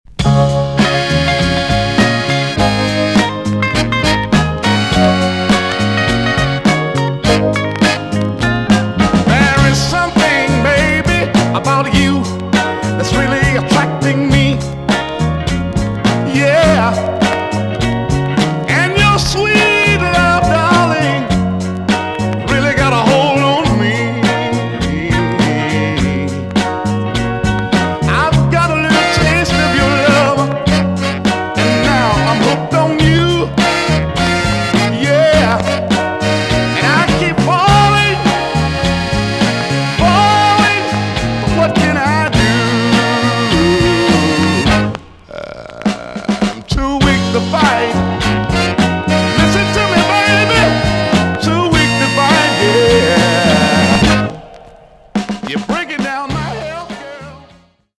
EX- 音はキレイです。
WICKED SOUL TUNE!!